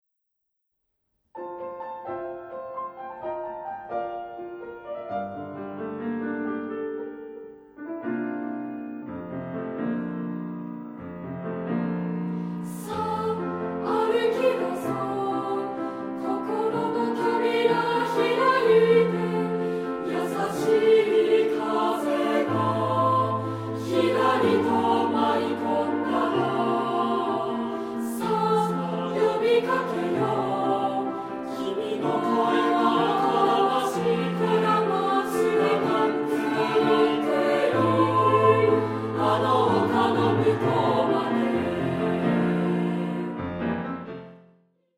楽譜 合唱
全曲収録ＣＤ付き楽譜［解説付き］
混声3部合唱／伴奏：ピアノ